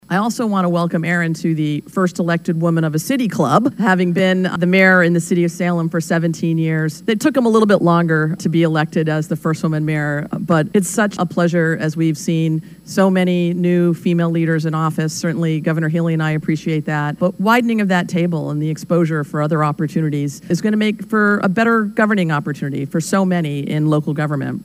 Lt. Governor Kim Driscoll administered the oath of office on Tuesday to the town’s third mayor, and first woman elected to that position, with Driscoll saying that her and Joyce were part of a growing sisterhood.